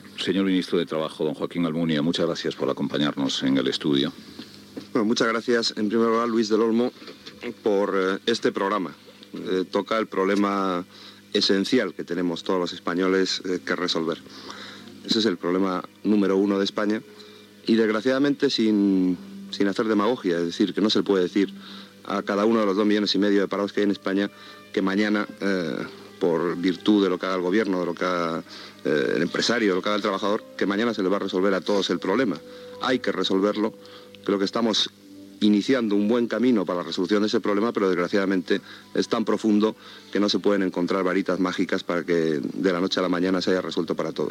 Persones presents a l'estudi i trucades telefòniques.